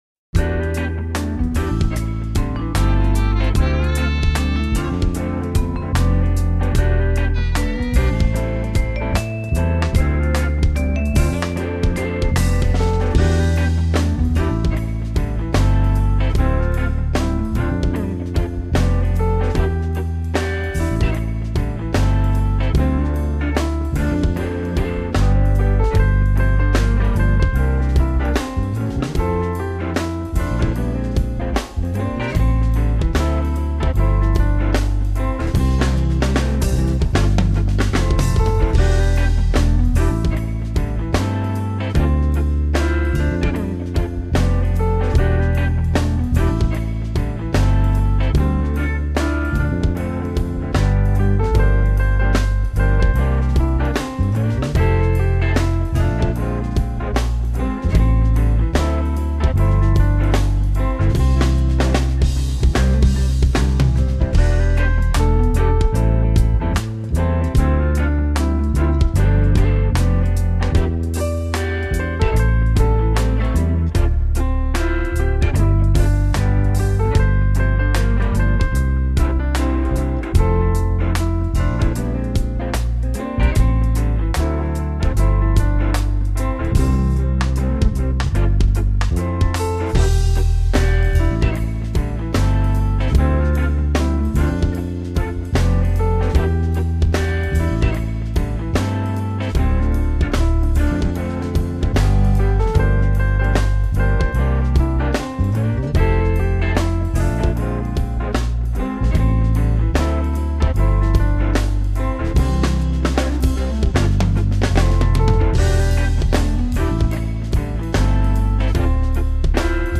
My backing is more Soul than Gospel.